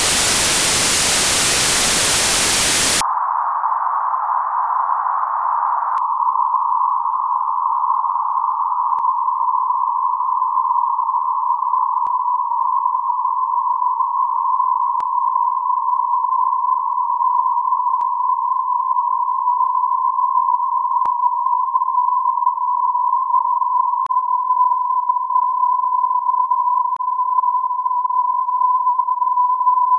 Can be done using the equalizer or this “Ultra Narrow” plugin.
What I need to do is produce a sample of white noise at one very narrow frequency …